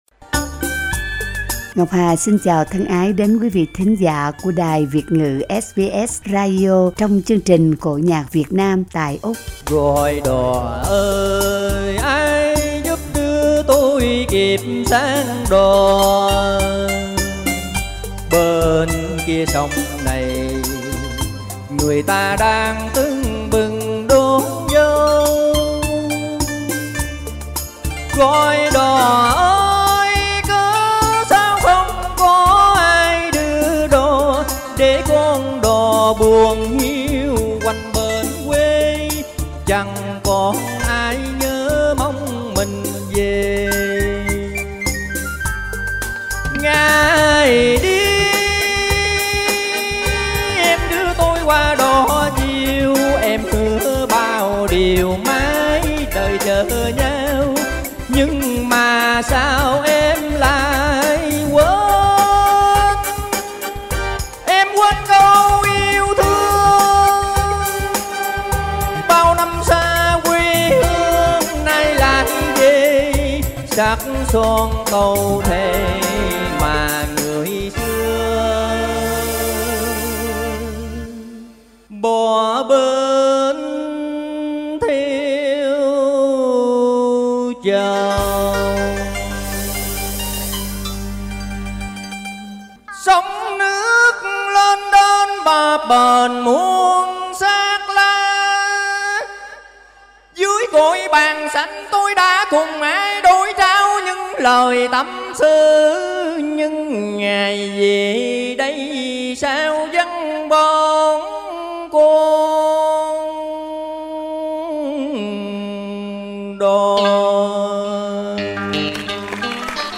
Cải Lương